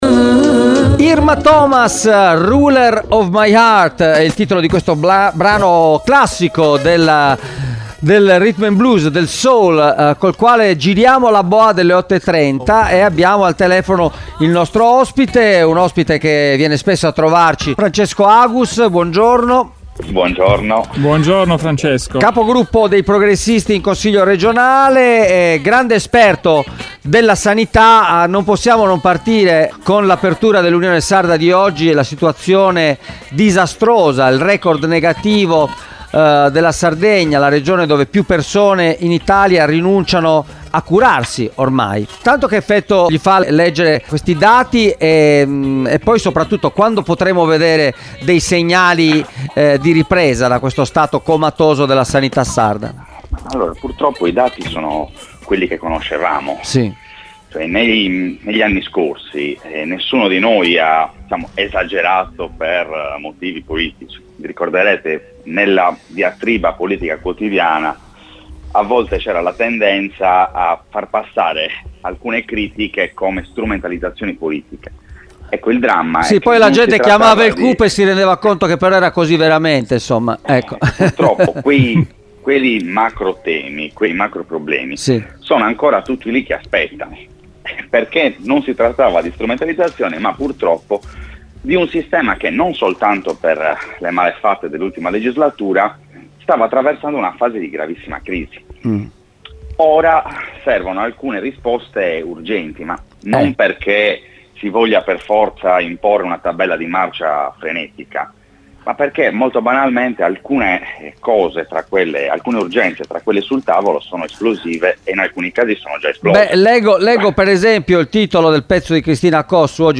Un confronto ancora da aprire con il neo assessore Bartolazzi per porre rimedio alla situazione critica del sistema sanitario, l’esigenza di intervenire sull’assalto eolico nei territori dell’isola, il numero eccessivo di consulenti di settore in capo a Villa Devoto e una legge sul maxi staff da abrogare quanto prima: Francesco Agus, capogruppo dei Progressisti in Consiglio regionale, è intervenuto questa mattina ai nostri microfoni per commentare la situazione della sanità in Sardegna, maglia nera in Italia per le persone che rinunciano alle cure, e per fare il punto sui prossimi passi della